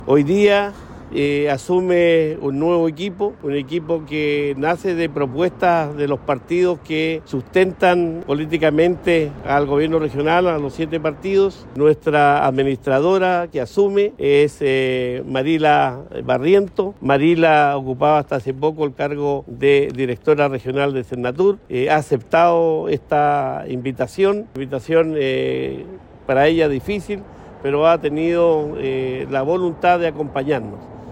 Así lo confirmó el gobernador Cuvertino.